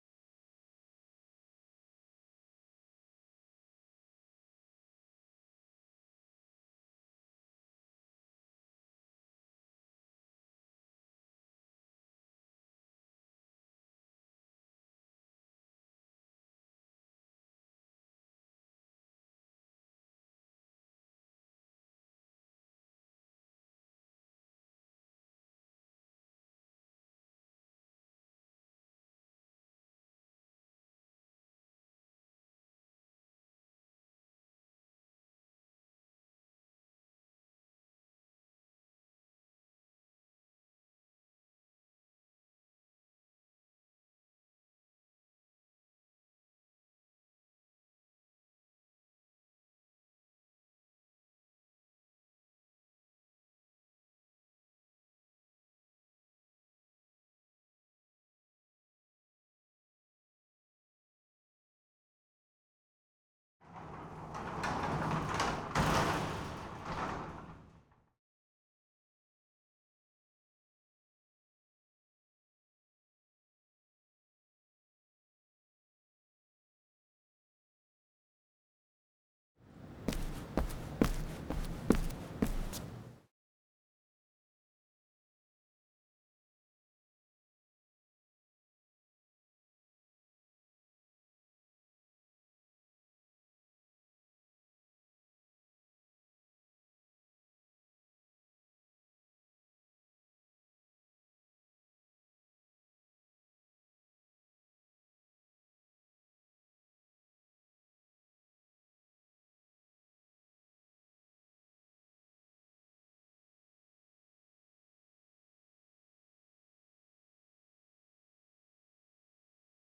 jeanne of the jonese_tca_cut 14 LONG VERSION_rev1_ST SFX.wav